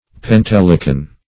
Pentelican \Pen*tel"i*can\